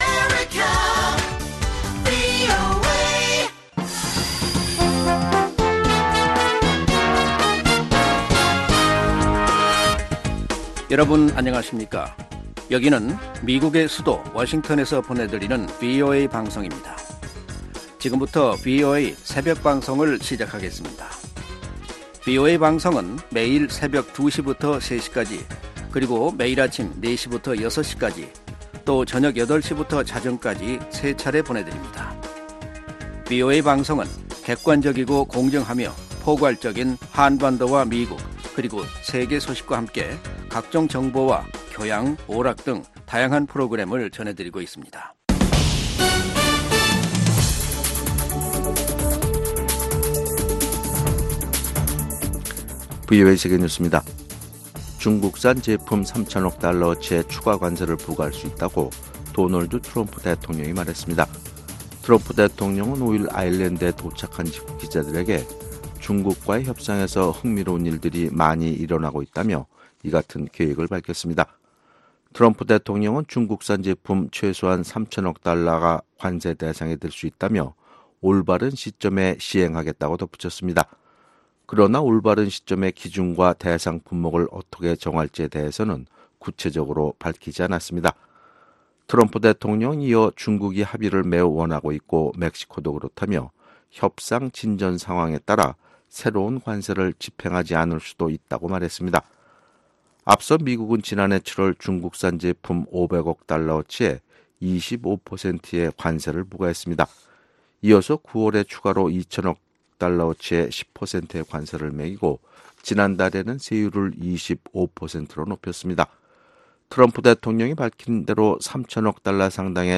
VOA 한국어 '출발 뉴스 쇼', 2019년 6월 7일 방송입니다. 도널드 트럼프 대통령은 김정은 위원장과 자신 모두 (비핵화) 합의를 바란다면서, 적절한 때 다시 만나길 고대한다고 말했습니다. 패트릭 섀넌핸 미 국방장관 대행은 일본 방문 중 아베 신조 총리와 북한의 완전한 비핵화에 대한 의지를 거듭 확인했다고 미국 국방부가 밝혔습니다.